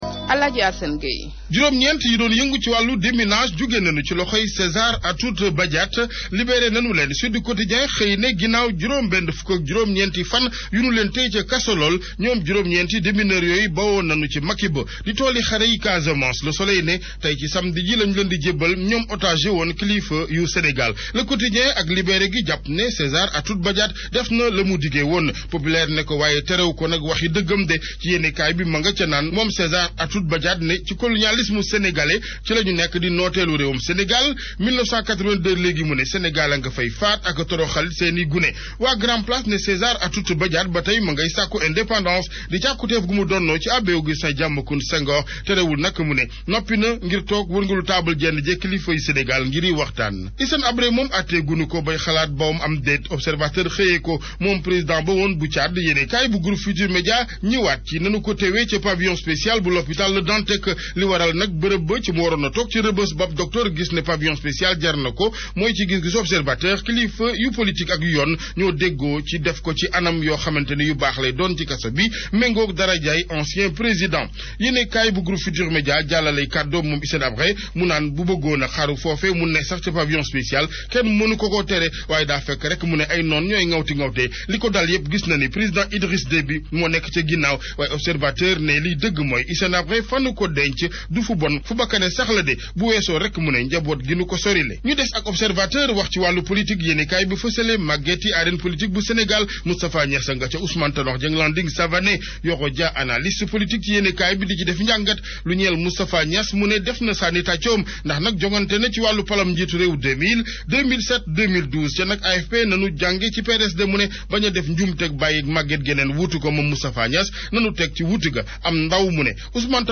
Revue de presse du 13 juillet 2013